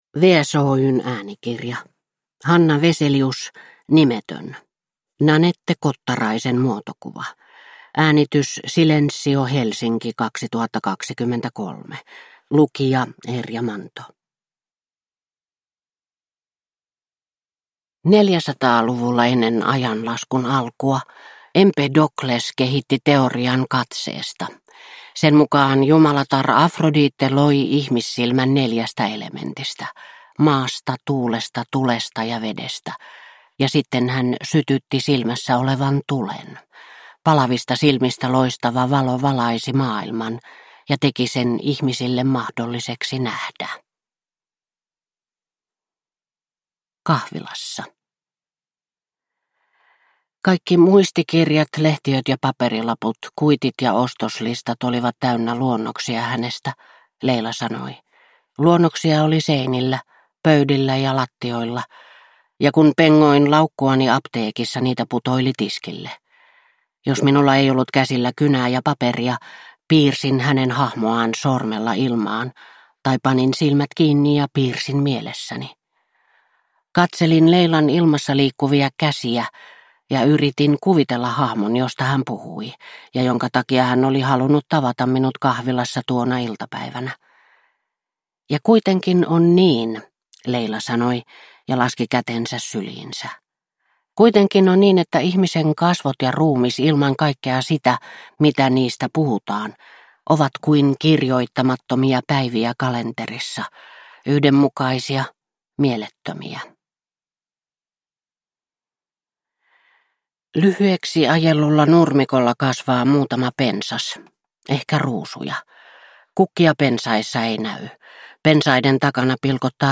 Nimetön – Ljudbok – Laddas ner